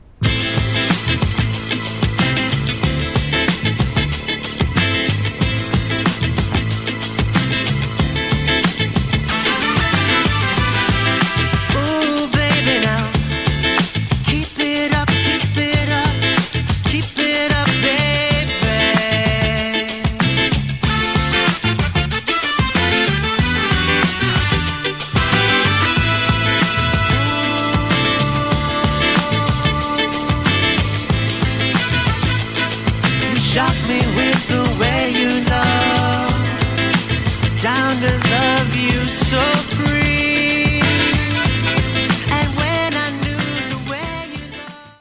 an exhilarating medium dancer full of West Coast flavor